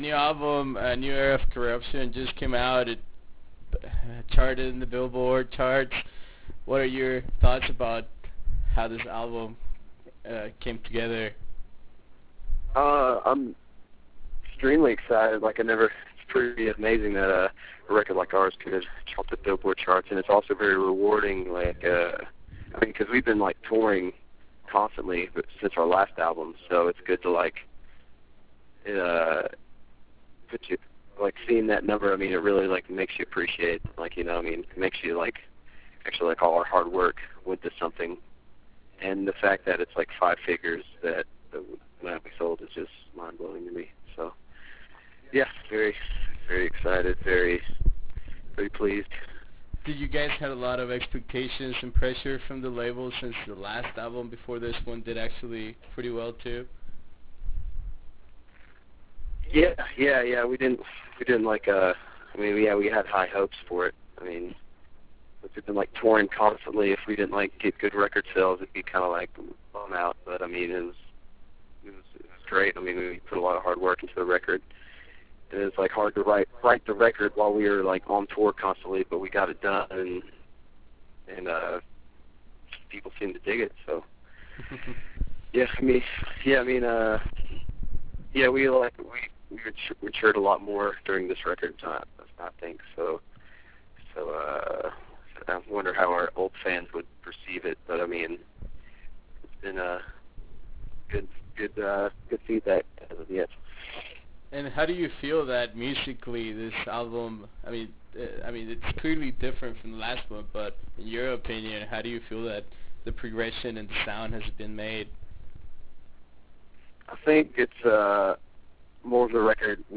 Interview with Whitechapel